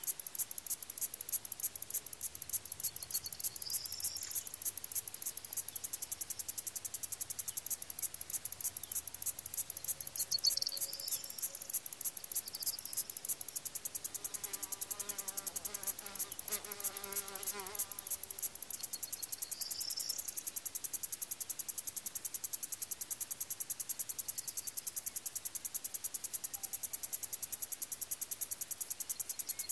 crickets_4.ogg